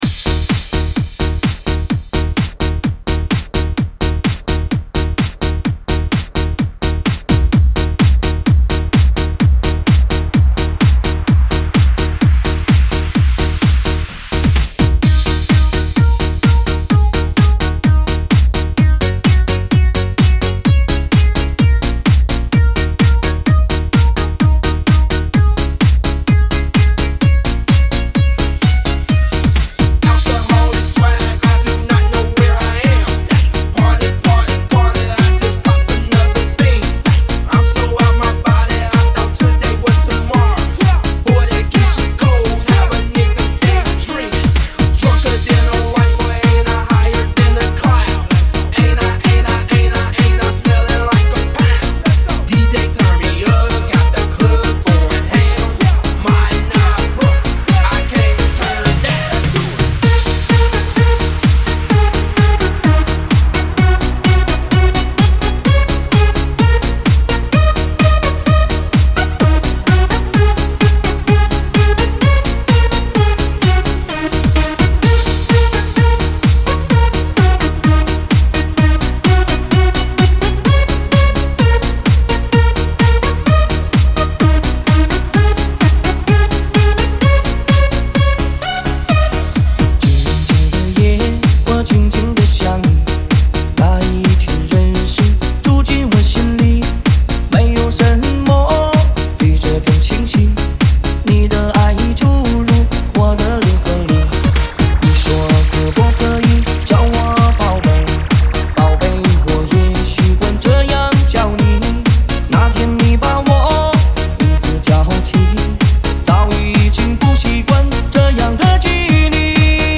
内地流行男歌手，新生代音乐唱作人、创作型歌手